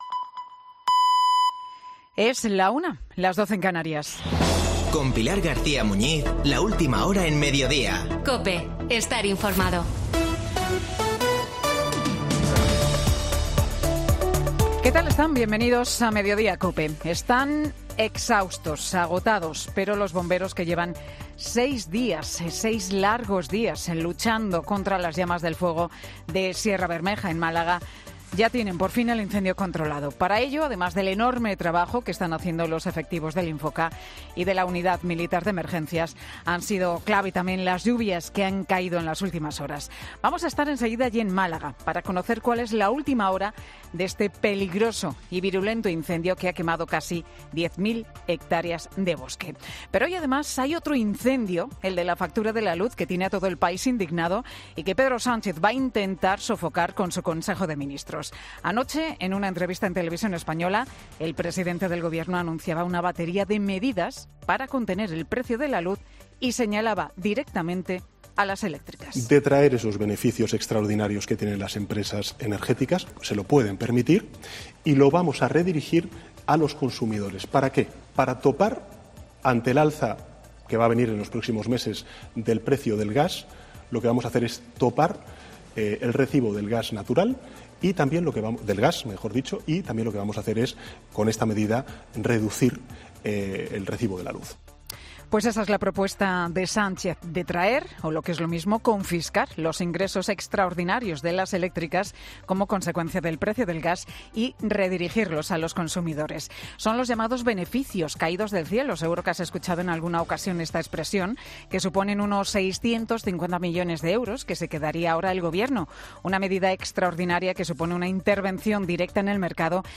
AUDIO: El mónologo de Pilar García Muñiz en Mediodía COPE